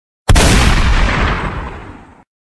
shotgun.ogg